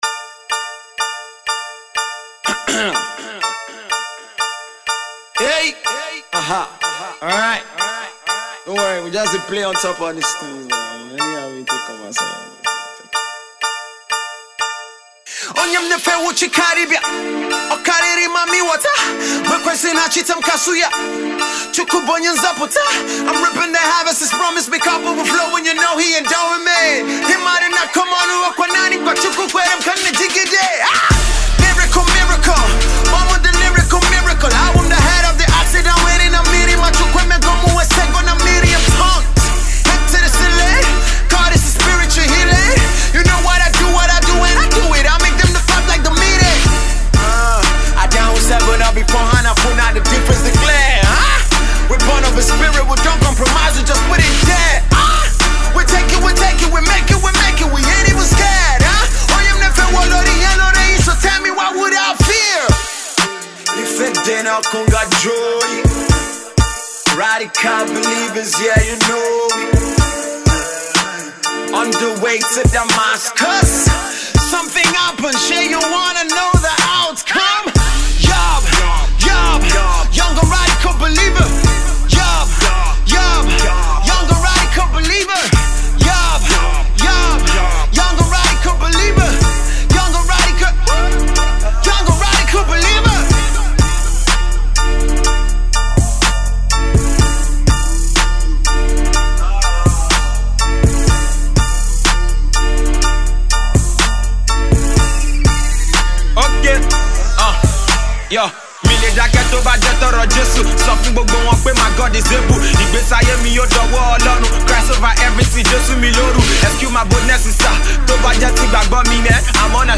indigenous rap
free Trap beat